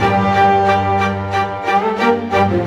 Rock-Pop 07 Orchestra 01.wav